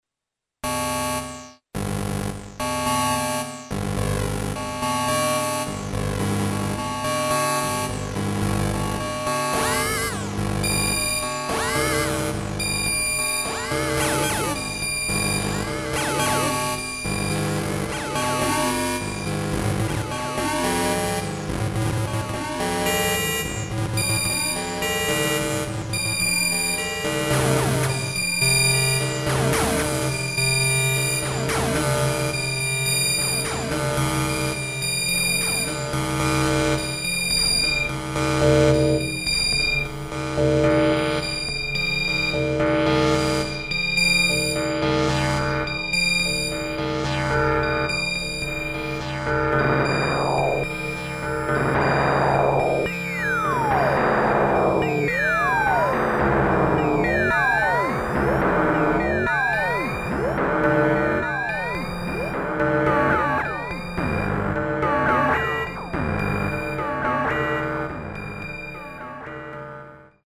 prototype electronic musical instruments
Unlike the self destructing vacuum tube circuits the Barrons used, the Martian Music Machines are solid state integrated analog synthesizers similar to the instruments made during the mid 1960's by Bob Moog and Don Buchla. Many of the complex tonal modulation sounds seem to take on lives of their own as they sing or sometimes scream in and out of existence.